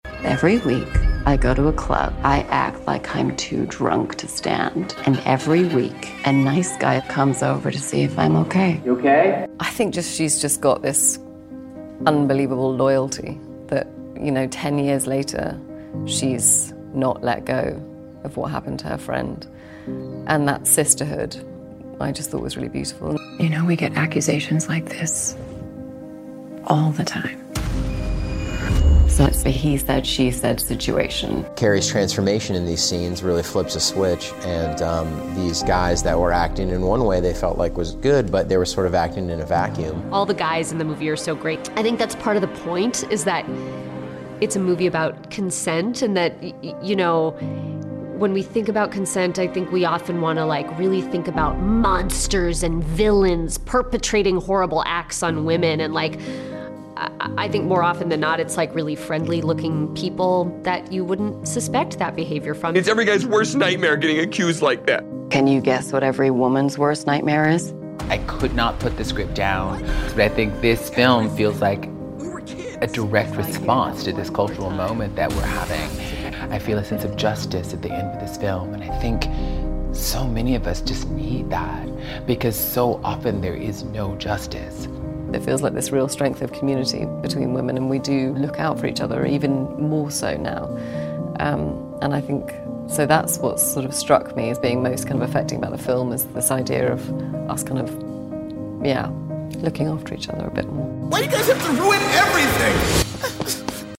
Carey Mulligan and the Promising Young Woman Cast Comment on the Complexities of the Culturally Relevant Film